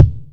Kicks
SWKICK01.wav